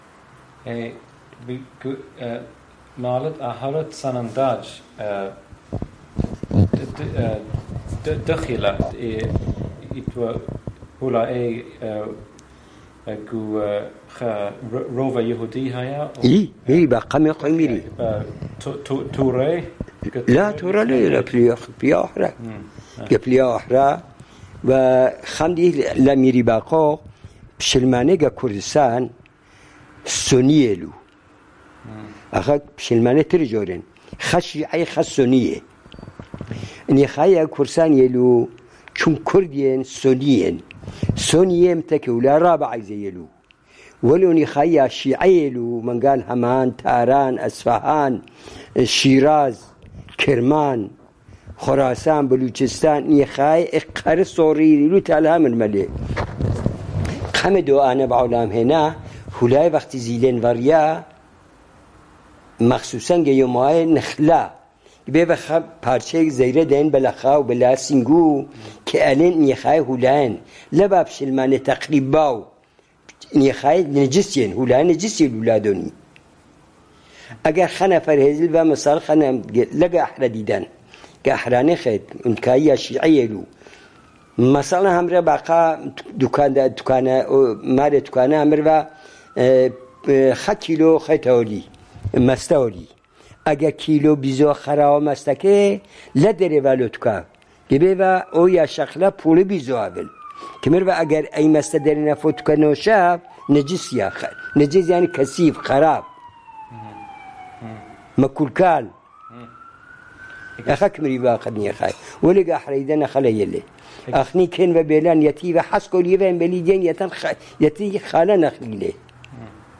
The Jewish Neo-Aramaic Dialect of Sanandaj.